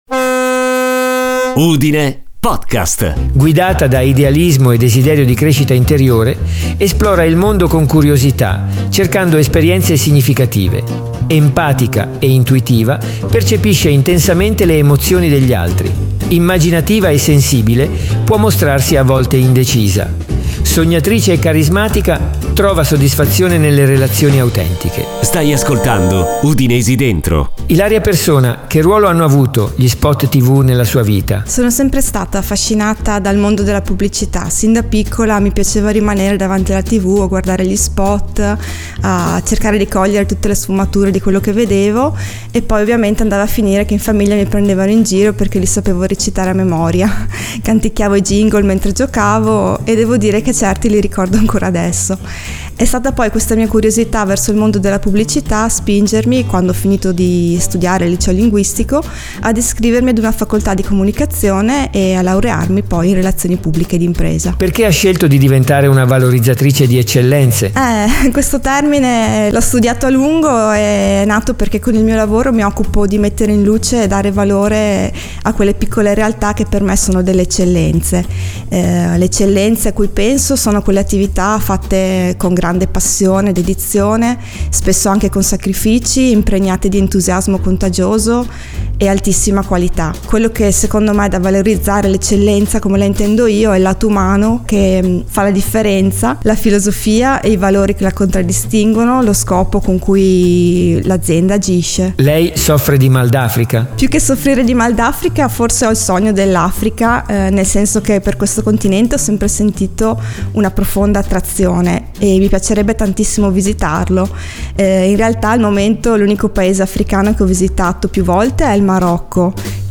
UDINESI DENTRO è un podcast originale